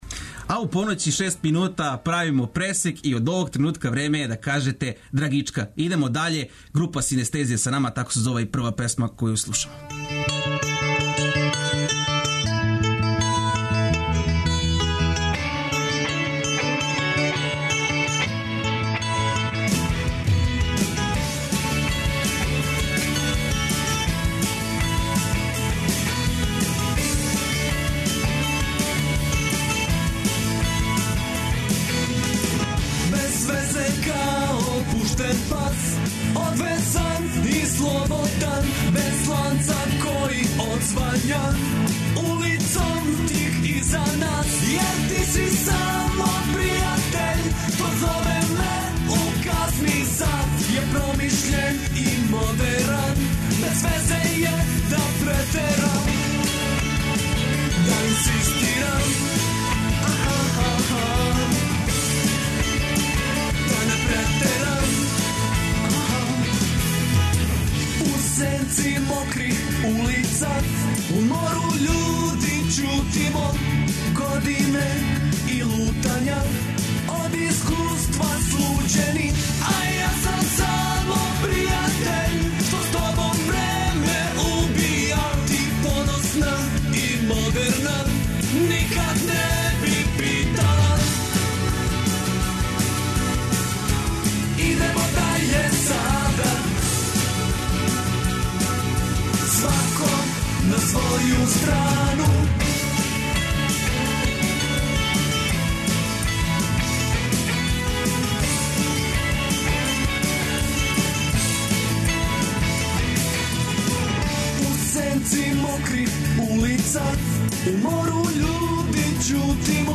Ноћас са нама група Синестезија, коју смо већ упознали кроз нашу емисију. Овог пута решили су да нам се представе кроз бендове које воле, и који су утицали на њих.